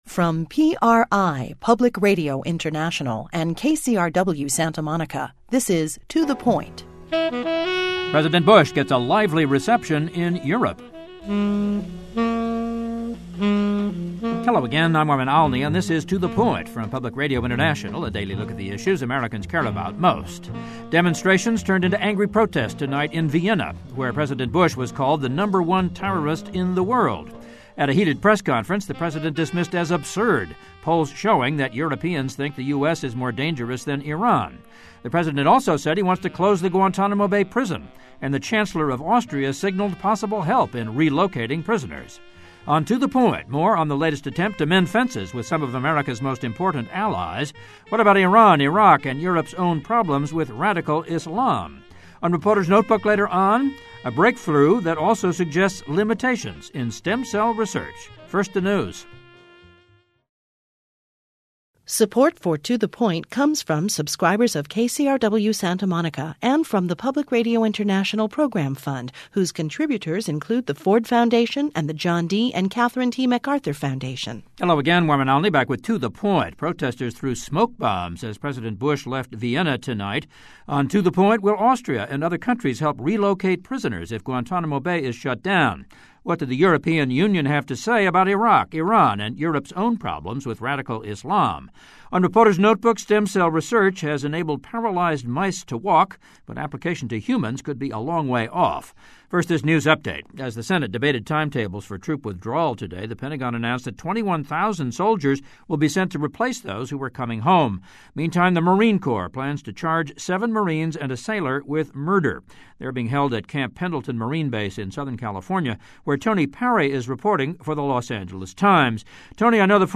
We hear more from political scientists and journalists in the US and Austria on the latest attempt to mend fences with America's most important allies.